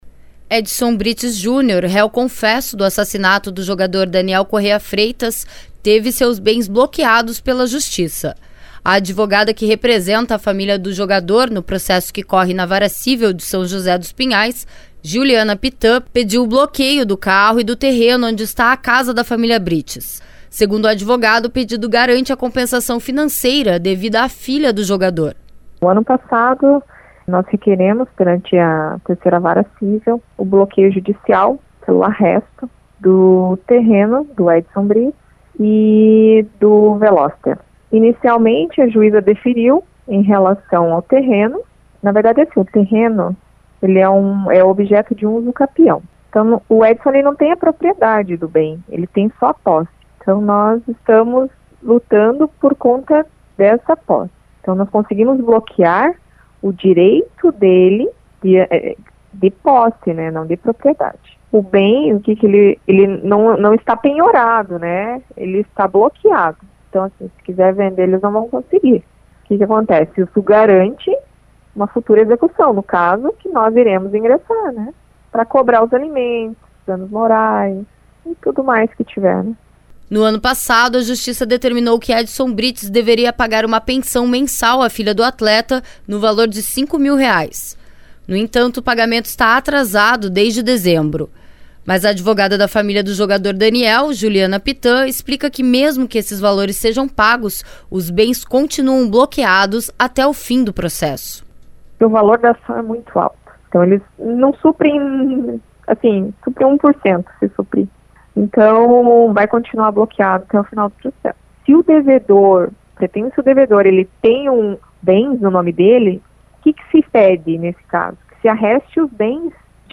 Os detalhes na reportagem.